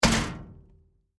trashmetal2.wav